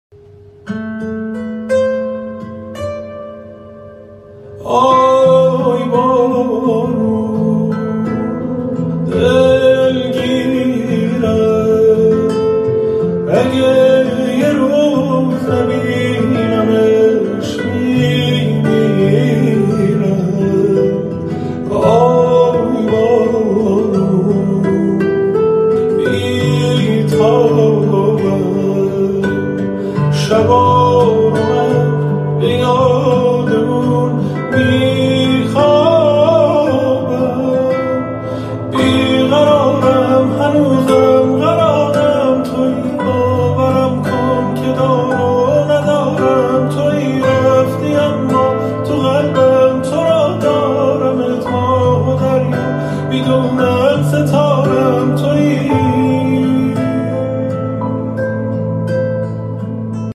عاشقانه و غمگین